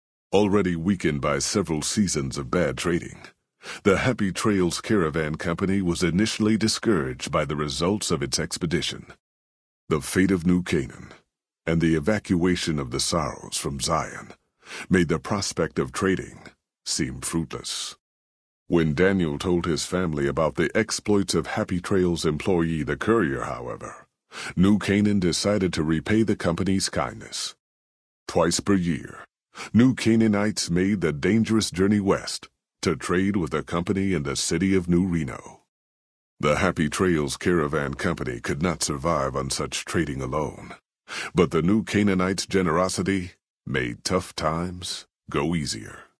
Category:Honest Hearts endgame narrations Du kannst diese Datei nicht überschreiben. Dateiverwendung Die folgende Seite verwendet diese Datei: Enden (Honest Hearts) Metadaten Diese Datei enthält weitere Informationen, die in der Regel von der Digitalkamera oder dem verwendeten Scanner stammen.